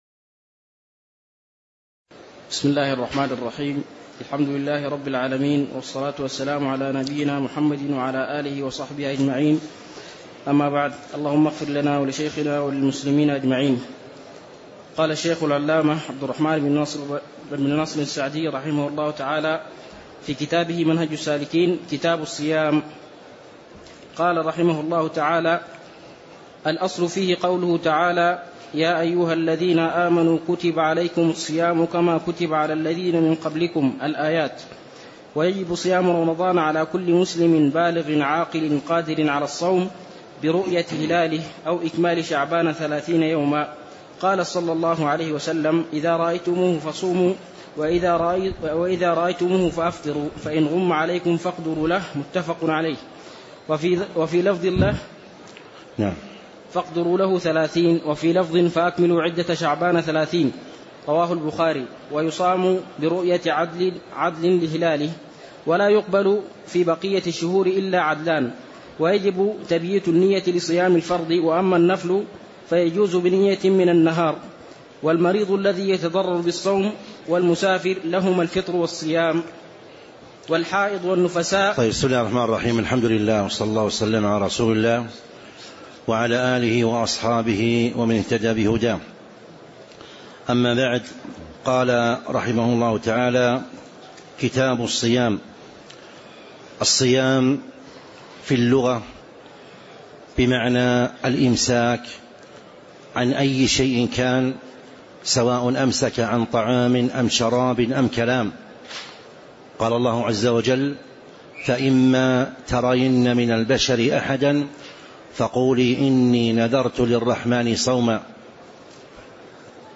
تاريخ النشر ٢٠ شعبان ١٤٤٦ هـ المكان: المسجد النبوي الشيخ